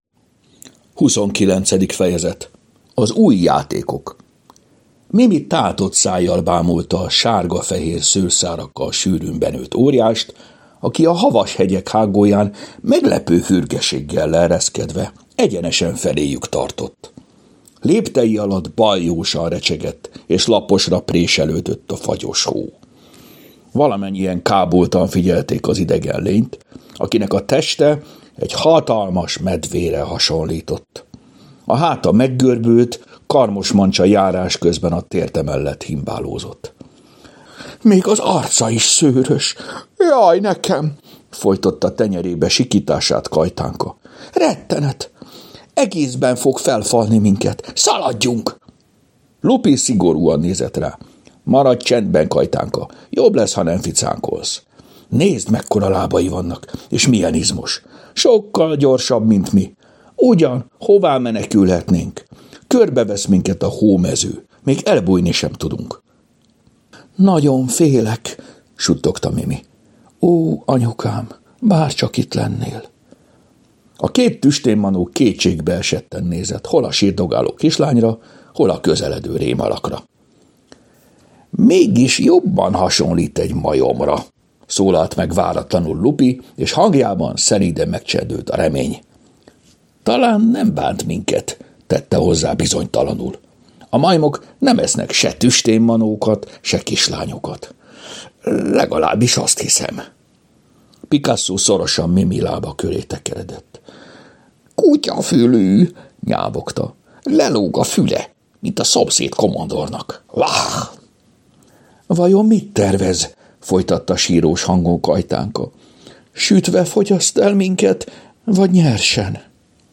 Hangos mese: Az új játékok Mindet meghallgatom ebből a folyamból!